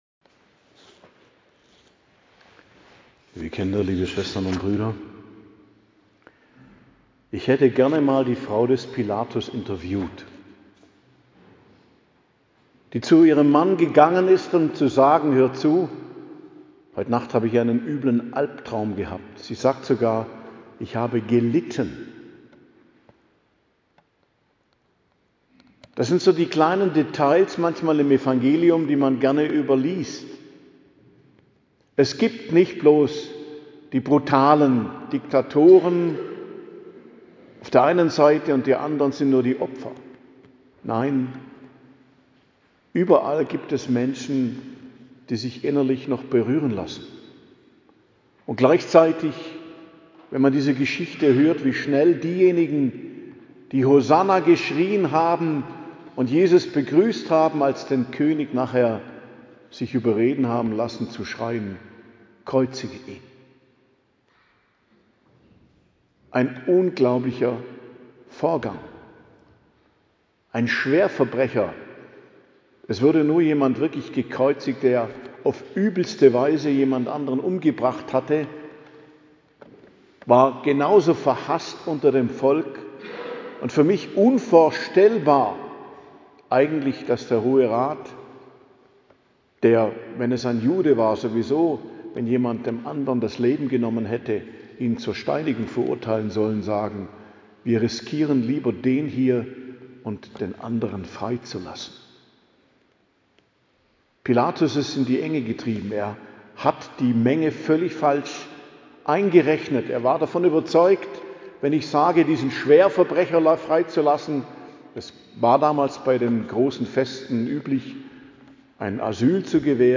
Predigt zum Palmsonntag, 29.03.2026